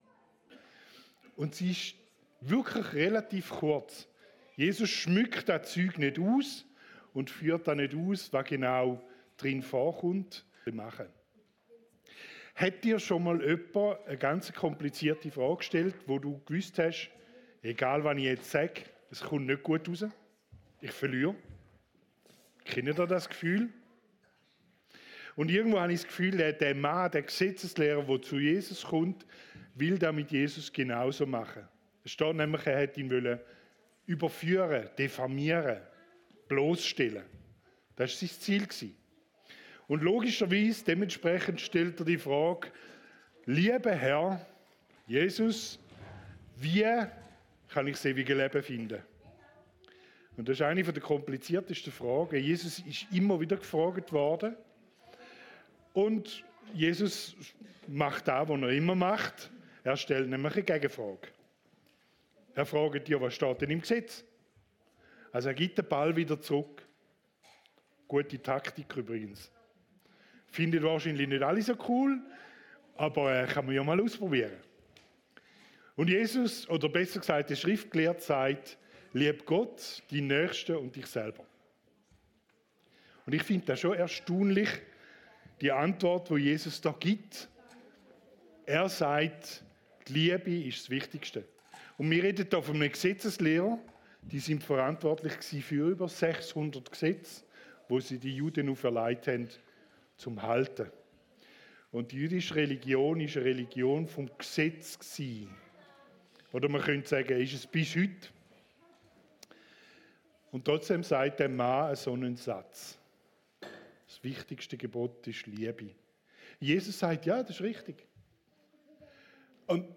EMK Herisau - Predigten